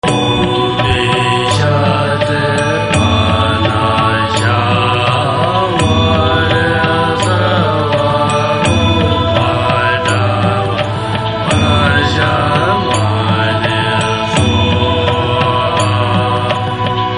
葉衣佛母心咒  (祥和版)